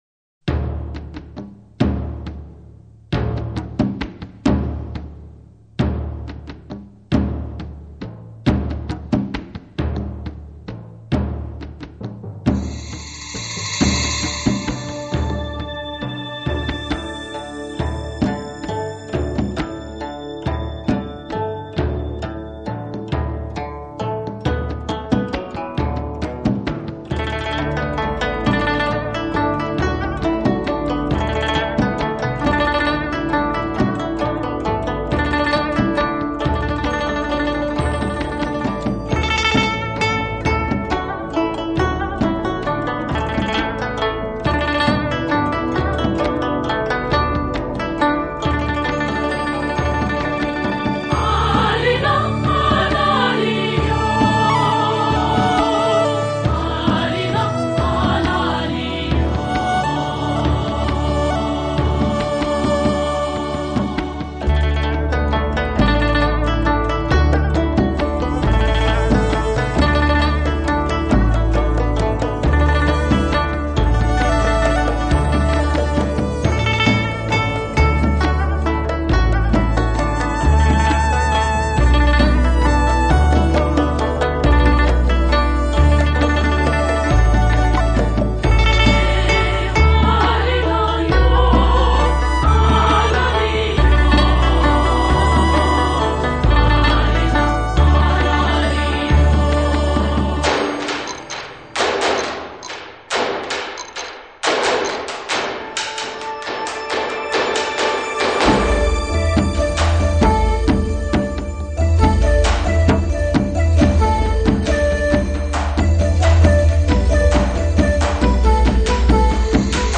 而芒鑼敲起、蘆管鳴響，更顯露出山林的原始與神秘。